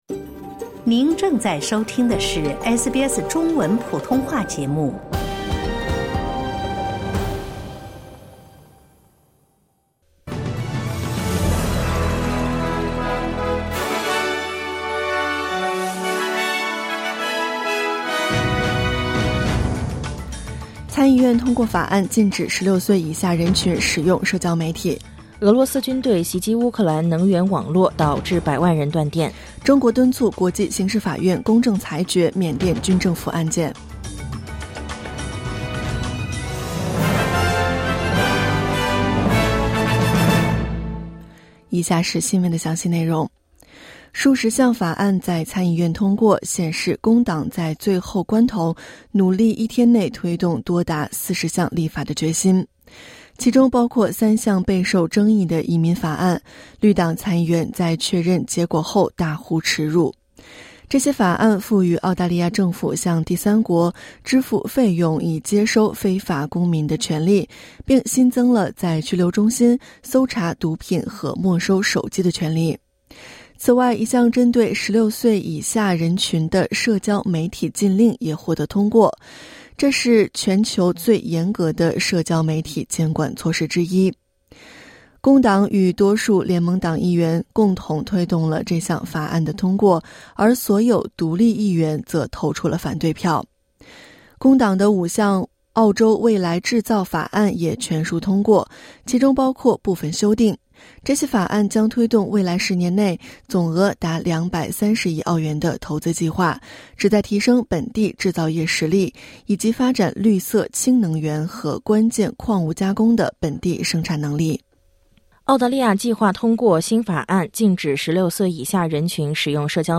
SBS早新闻（2024年11月29日）